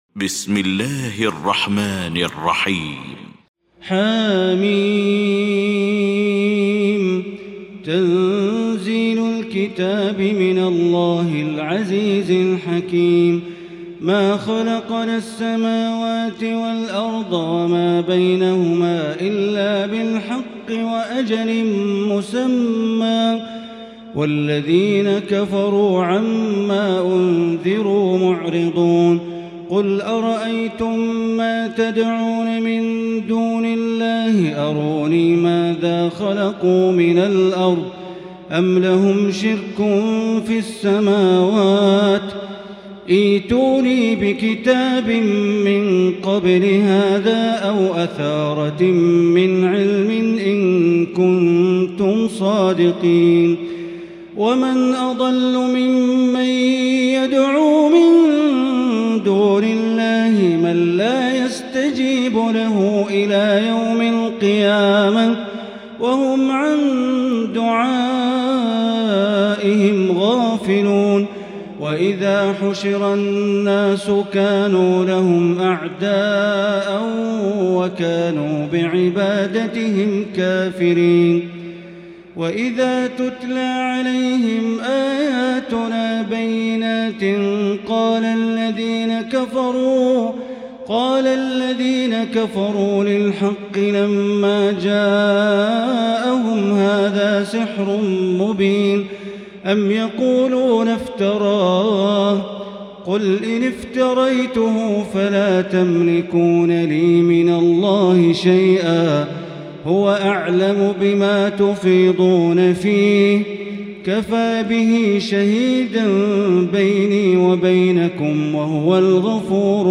المكان: المسجد الحرام الشيخ: معالي الشيخ أ.د. بندر بليلة معالي الشيخ أ.د. بندر بليلة الأحقاف